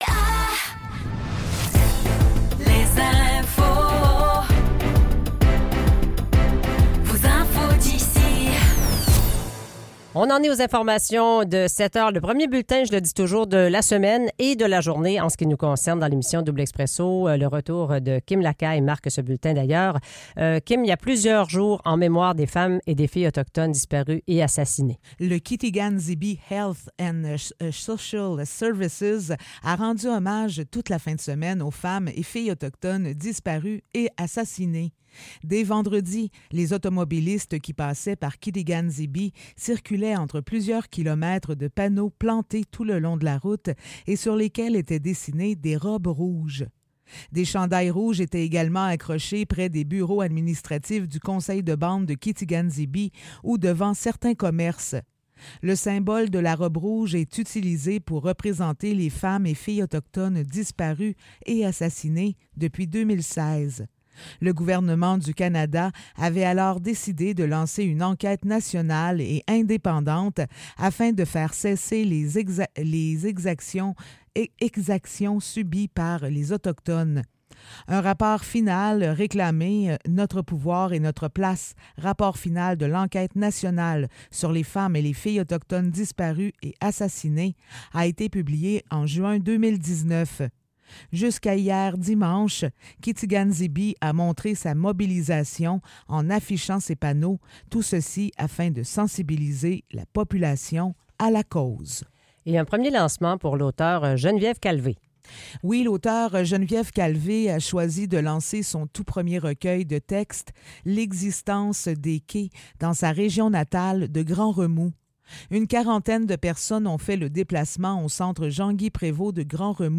Nouvelles locales - 6 mai 2024 - 7 h